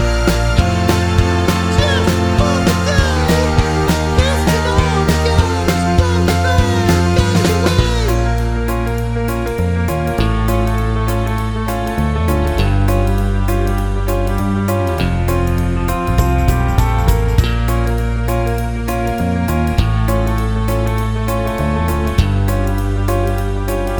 With Intro Voiceover Pop (2010s) 3:17 Buy £1.50